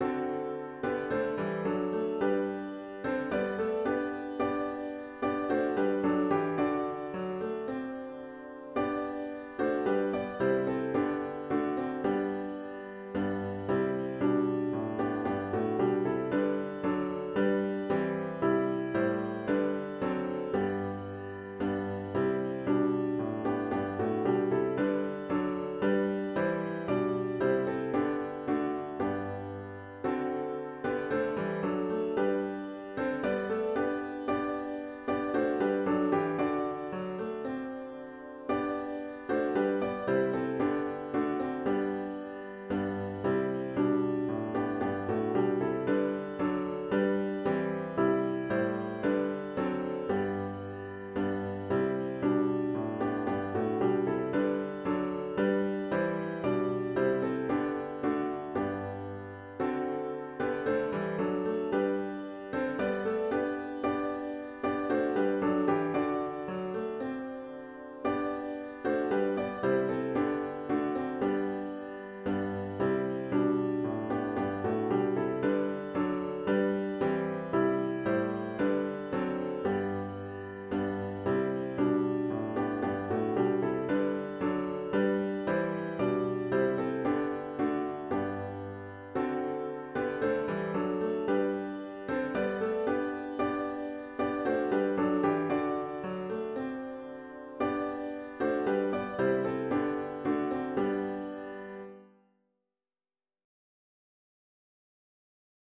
*OPENING HYMN “God of Grace and God of Glory” GtG 307 [Verses 1, 3, & 4]